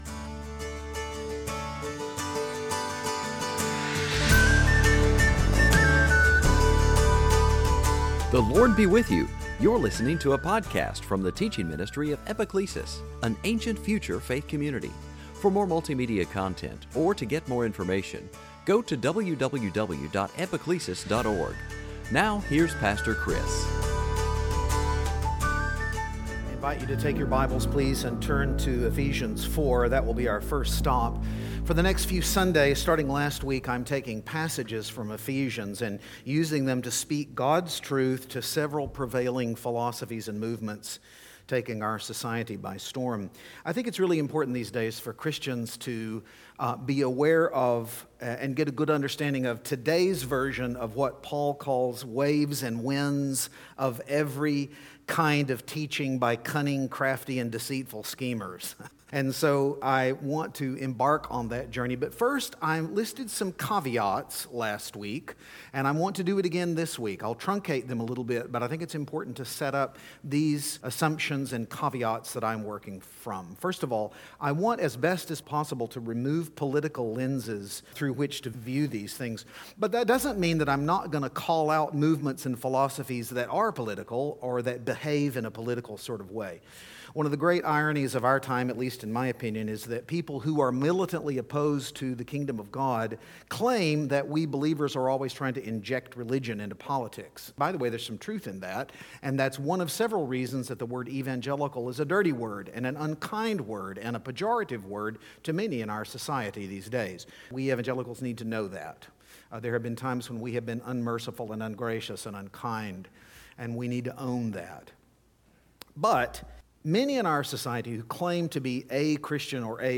In this sermon, we look again at Critical Theory and its consequences for the definition of terms, even truth itself, and it's compatibility with Kingdom values.